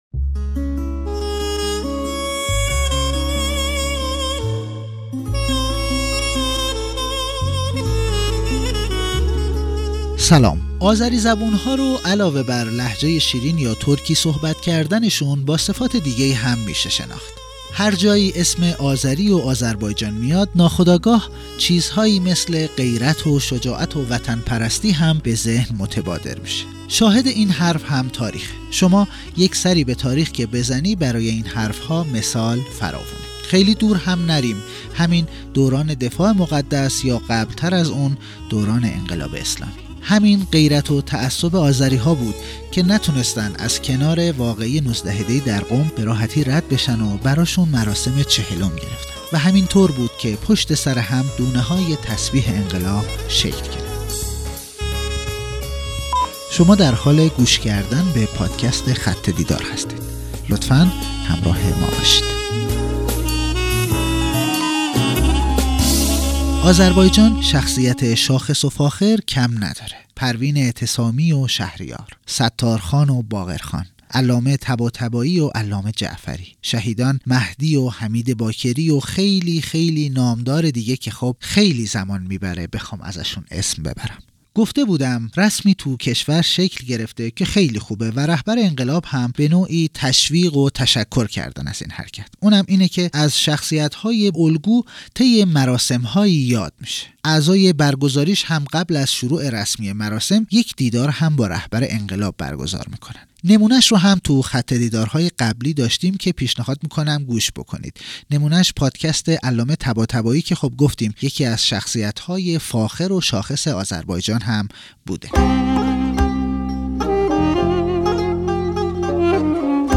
بیانات در دیدار اعضای ستاد مرکزی کنگره بزرگداشت ده هزار شهید آذربایجان شرقی